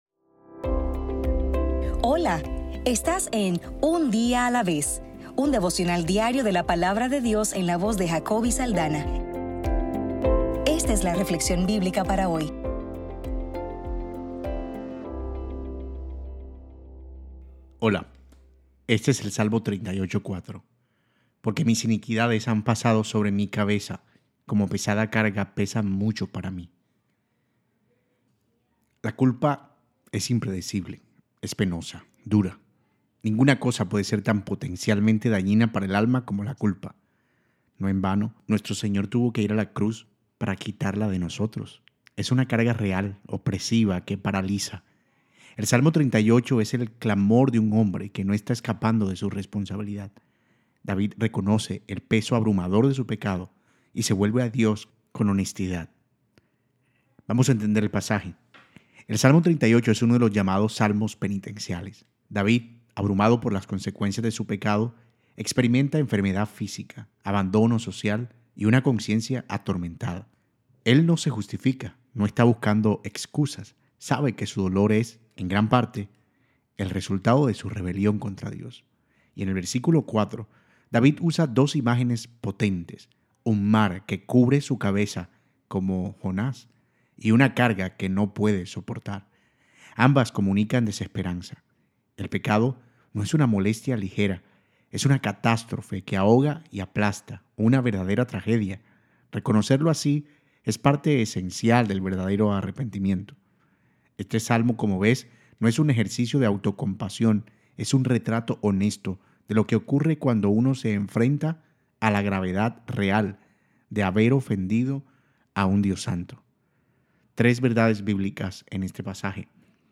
Devocional para el 27 de abril